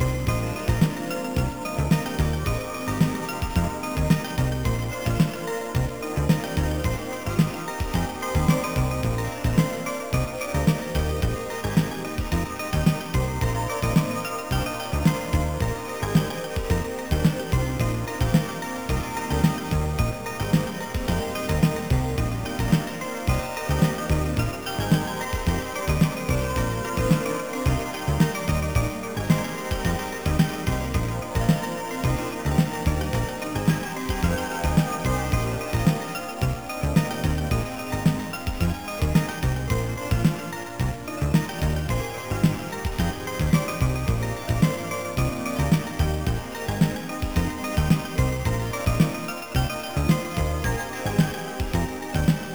MTRはTOA MR-8T。音源はROLAND JUNO-106とYAMAHA V2。シーケンサはROLAND MSQ-100。リズムマシンはYAMAHA RX100。マルチエフェクタはYAMAHA REX50。